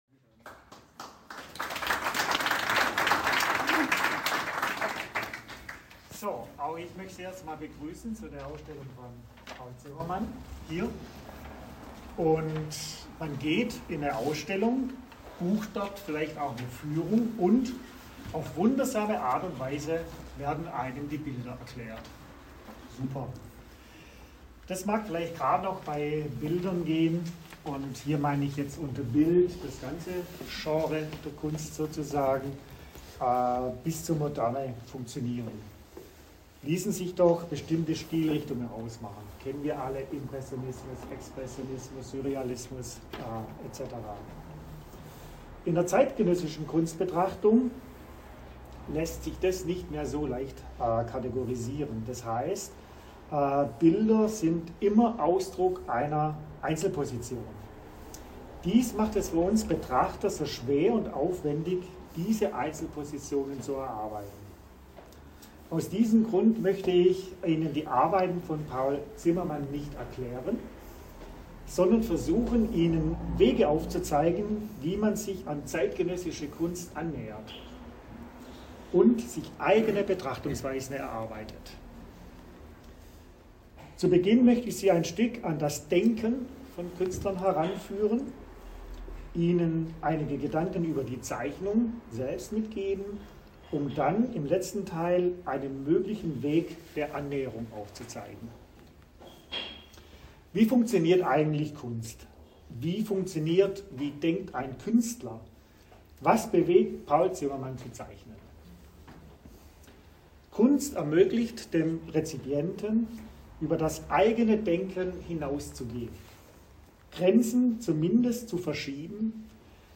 Einführungsrede bei der Vernissage
Rede-Vernissage.mp3